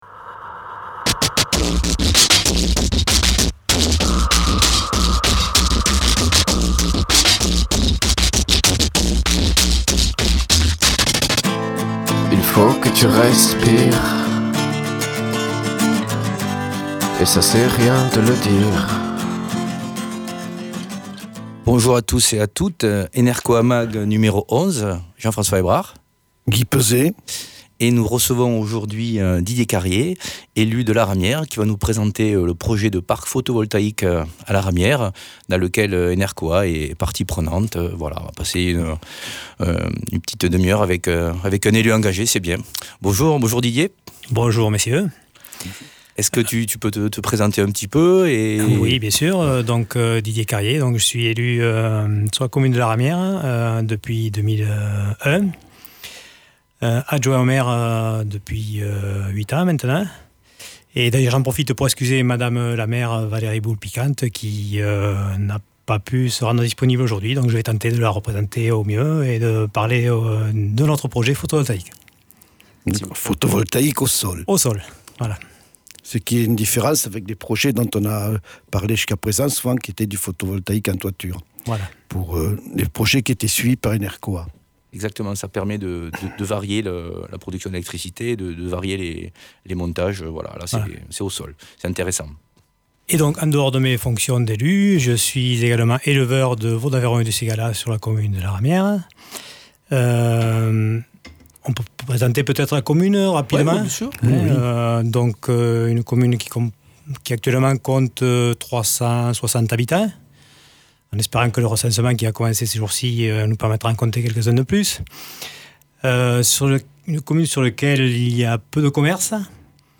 Invité(s) : Didier Carrié, élu, Laramière